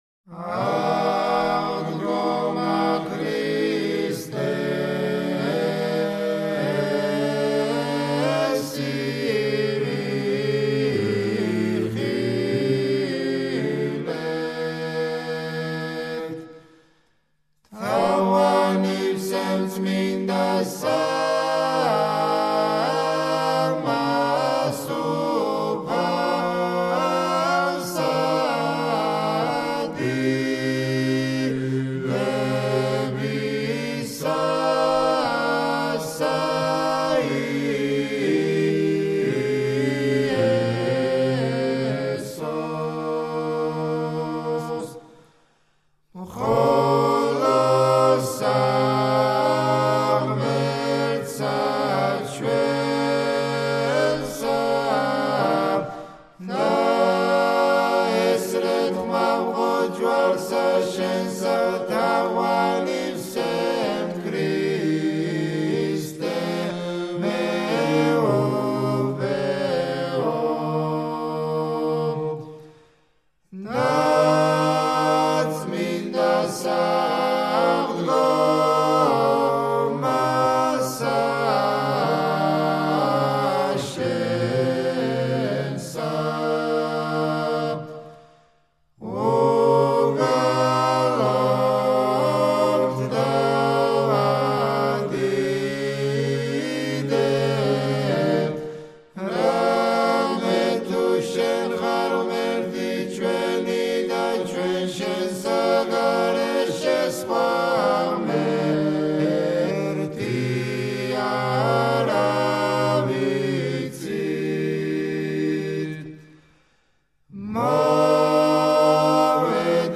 აღდგომა ქრისტესი - საგალობელი
სკოლა: გელათის სკოლა